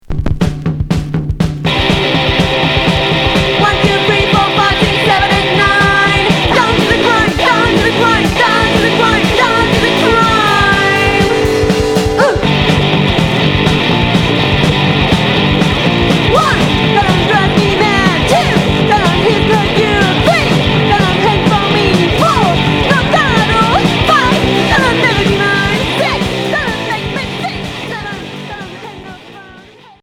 Punk garage